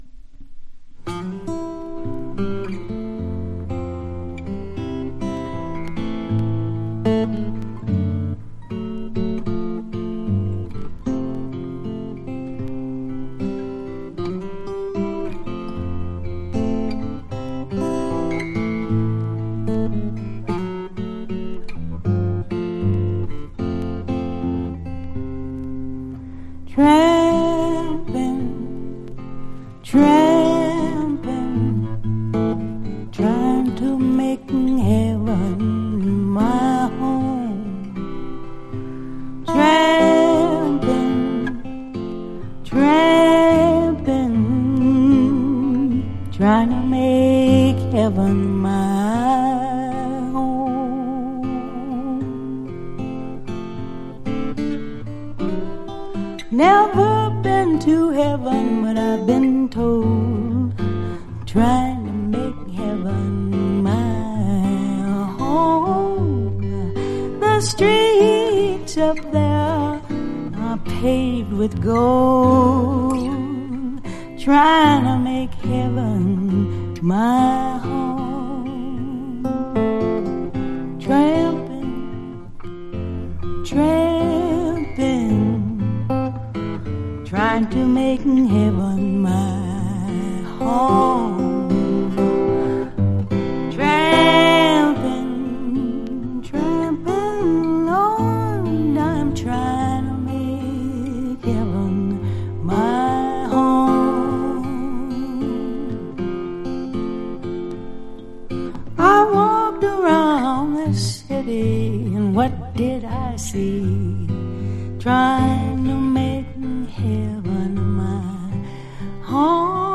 女性ボーカル